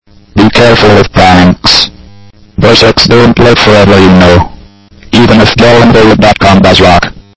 Made myself a text to speech server today: Festival
It sounds much better than this on the computer but I guess I lost some quality in converting it to mp3